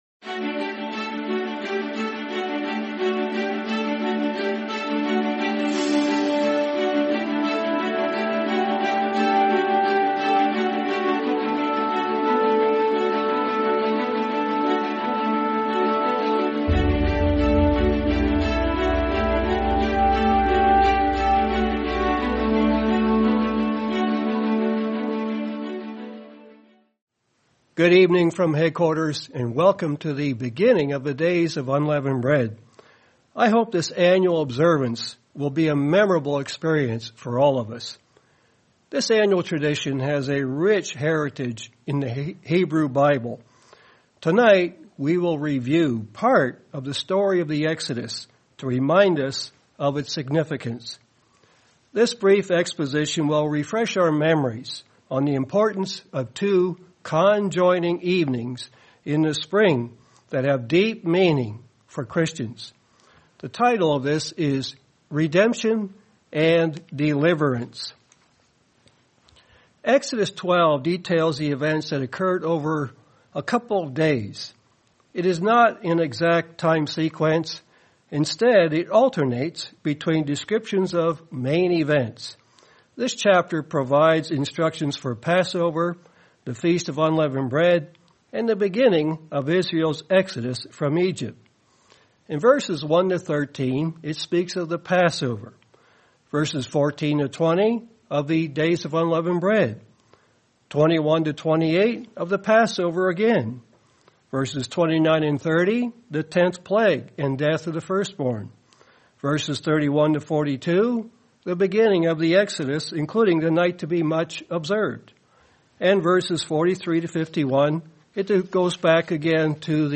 Sermon Night to Be Much Observed